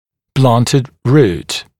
[‘blʌntɪd ruːt][‘блантид ру:т]тупоконечный корень